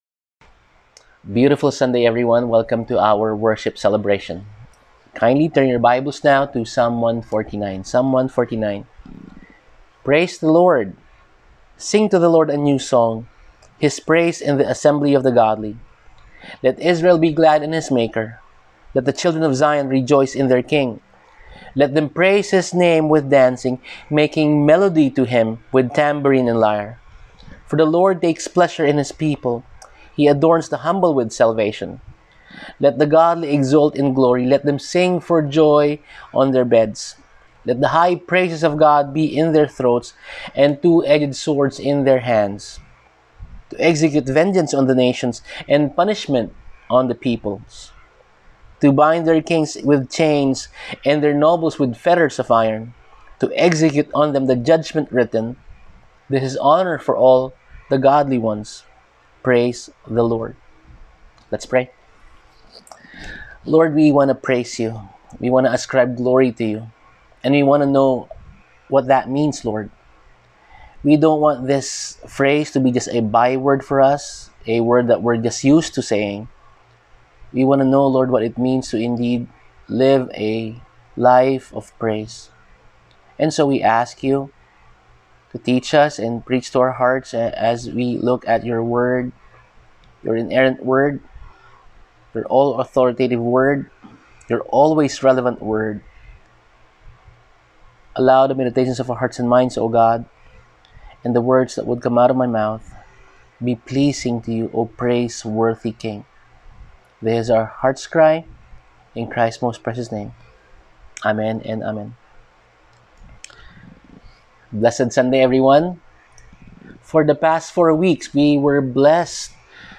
Service: Sunday Sermon